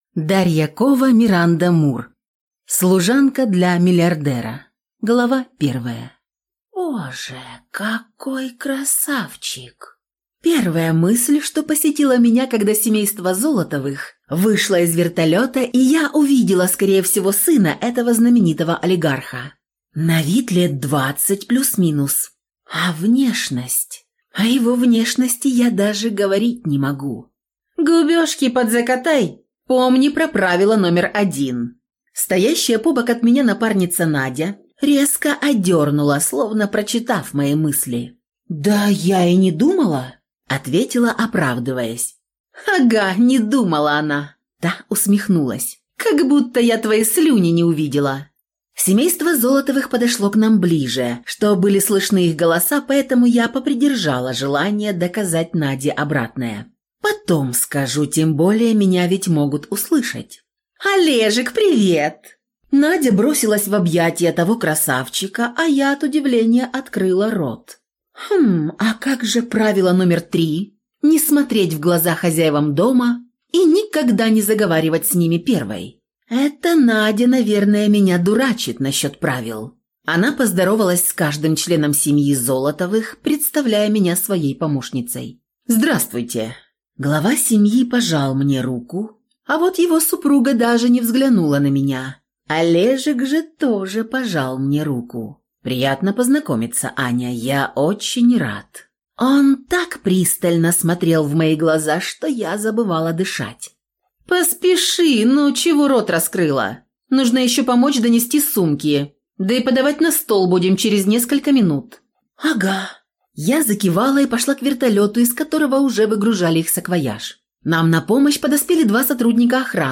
Аудиокнига Служанка для миллиардера | Библиотека аудиокниг